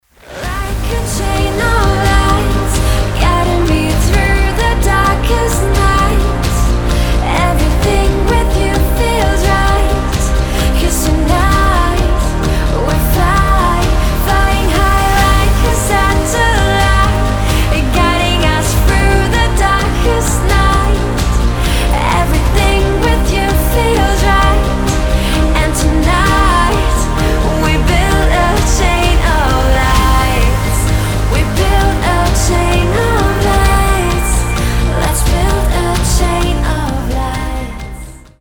• Качество: 320, Stereo
приятные
красивый женский голос